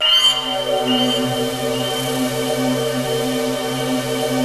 ATMOPAD21.wav